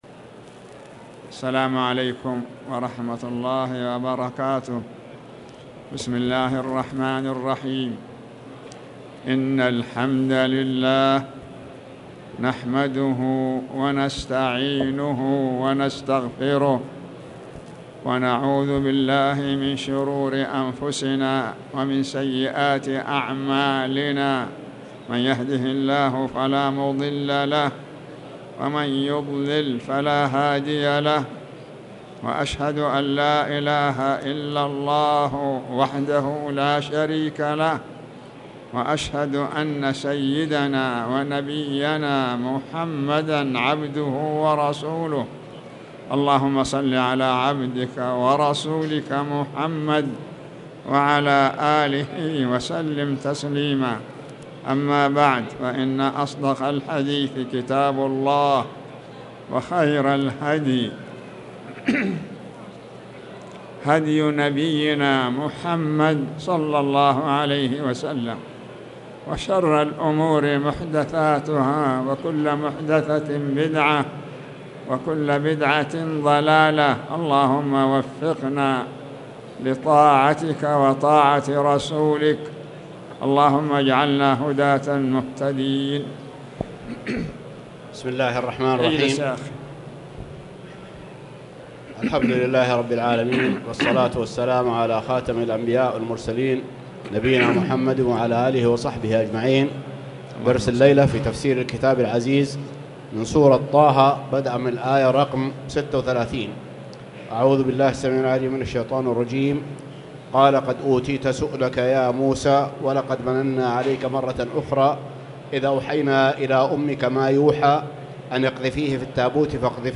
تاريخ النشر ٩ جمادى الآخرة ١٤٣٨ هـ المكان: المسجد الحرام الشيخ